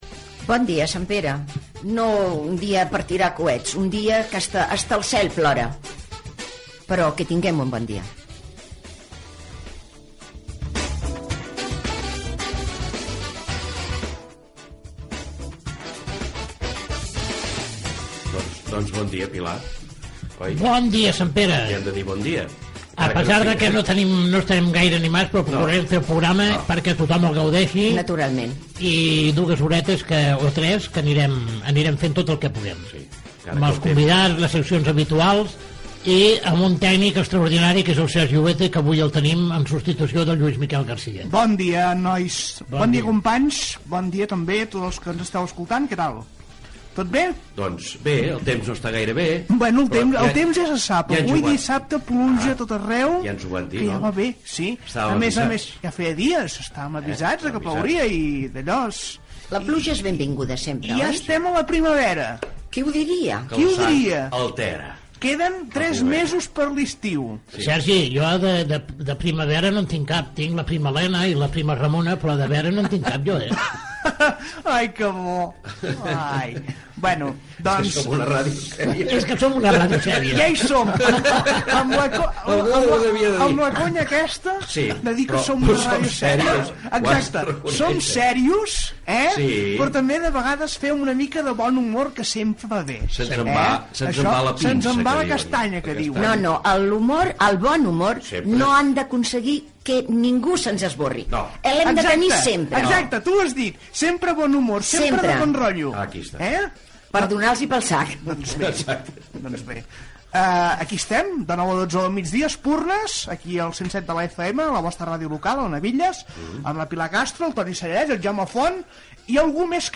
Diàleg entre els presentadors, equip del programa, presentació de l'invitat que vindrà al programa, el santoral, dita popular
Entreteniment
FM